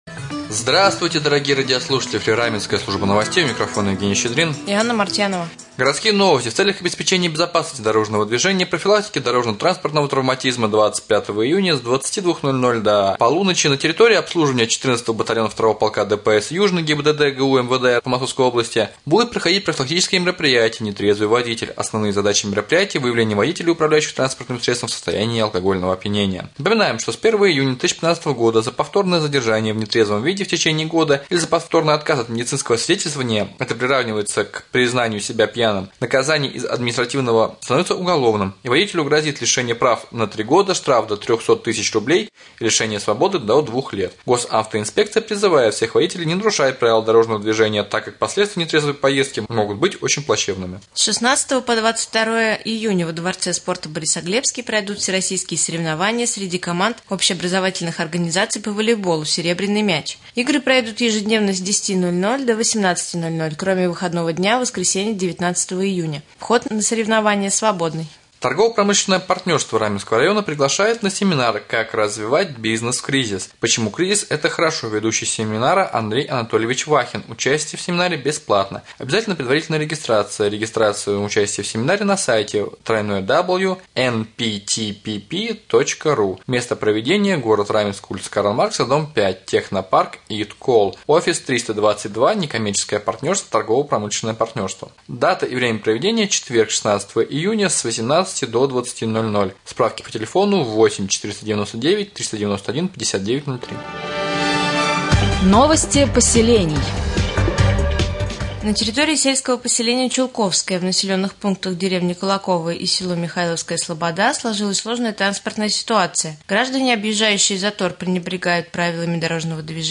1. Новости 2. Прямой эфир с главой района Владимиром Деминым